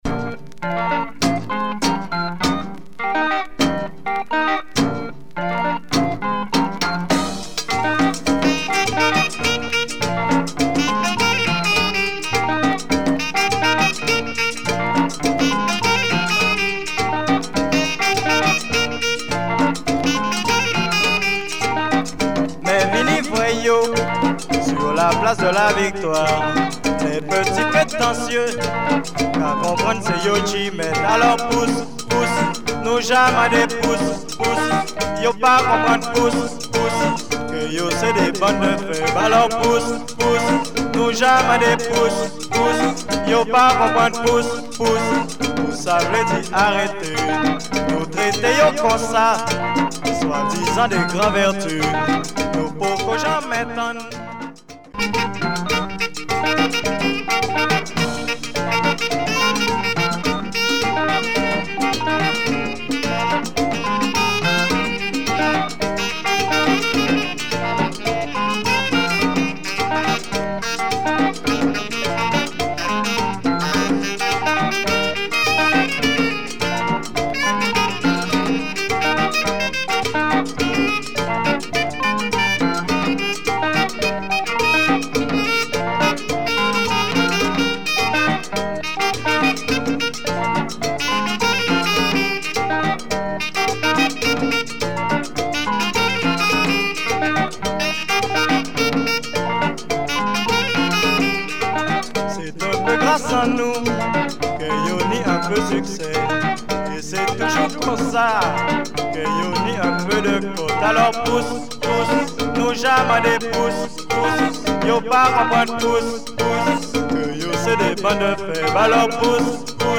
Caribbean